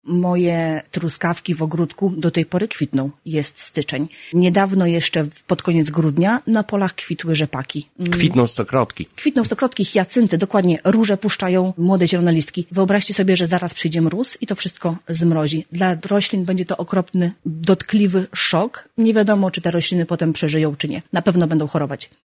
O wyjątkowo nietypowej aurze, jaką mamy tej zimy, rozmawialiśmy na antenie Radia 5